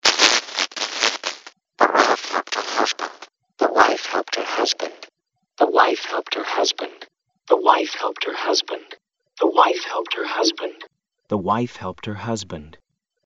Cochlear Implant Simulation
This demonstration simulates what speech and music would sound like through a cochlear implant.
This envelope signal is used to modulate a wide-band white noise, which is then filtered with a band-pass filter, usually the same filter used in the analysis.
However, Increase_Channels presents the demonstration in the reverse order - starting from poor quality, so that the content of the sentence is not understood at first.
From 4 to 8 to 32 channels the words are clearer and the sound has better quality. Finally, the original sentence is played.